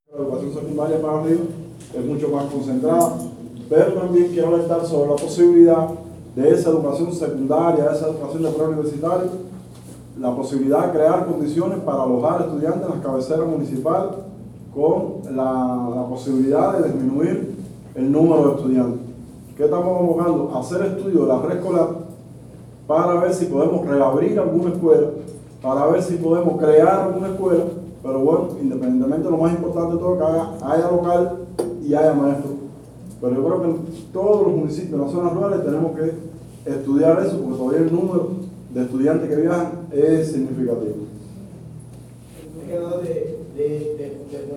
Por su parte, el Director provincial de Educación, Edilberto Casanova Armenteros, expresó la preocupación con respecto a los estudiantes a los que se les dificulta la transportación a los centros educacionales.
AUDIO-DIRECTOR-DE-EDUCACION.mp3